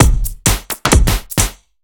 OTG_Kit 4_HeavySwing_130-D.wav